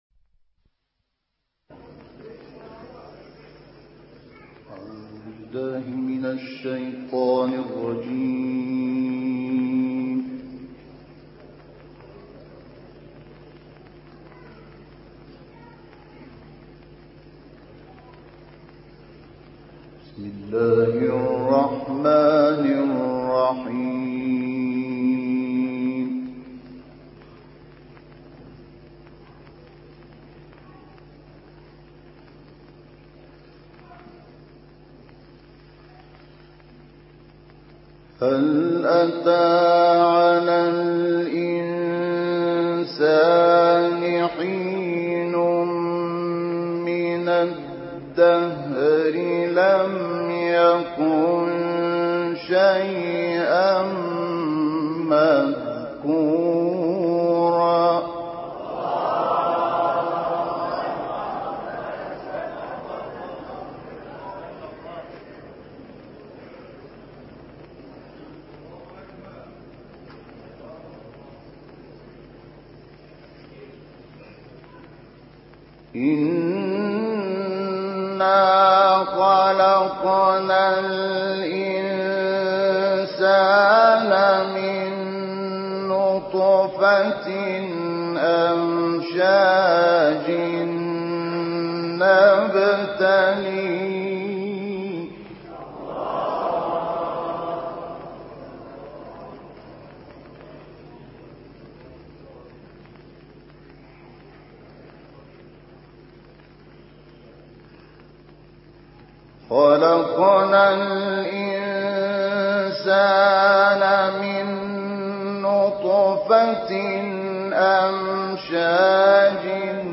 تلاوت آیاتی از سوره انسان توسط مرحوم شحات محمد انور